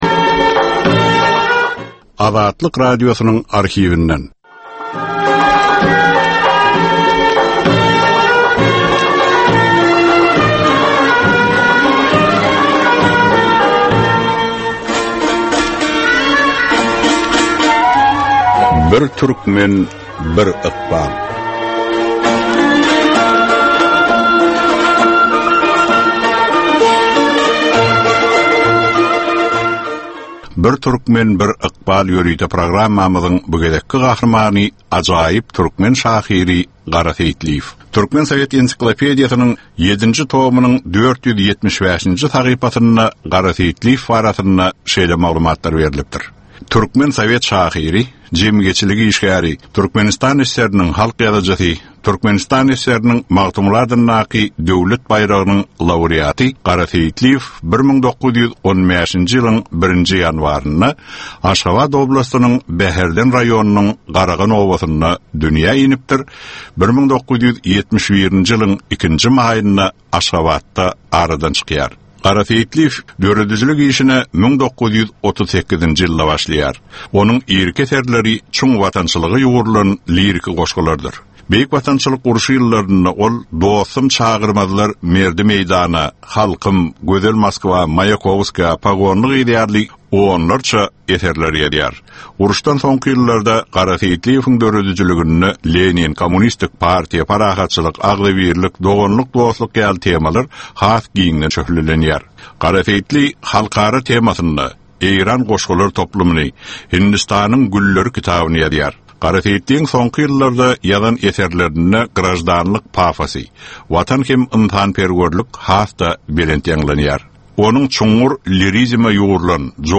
Türkmenistan we türkmen halky bilen ykbaly baglanyşykly görnükli şahsyýetleriň ömri we işi barada 55 minutlyk ýörite gepleşik. Bu gepleşikde gürrüňi edilýän gahrymanyň ömri we işi barada giňişleýin arhiw materiallary, dürli kärdäki adamlaryň, synçylaryň, bilermenleriň pikirleri, ýatlamalary we maglumatlary berilýär.